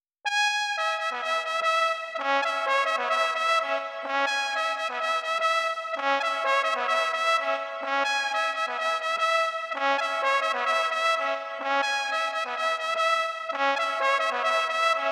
trombone_saxo_8mesures.wav